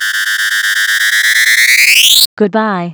cortex_countdown.wav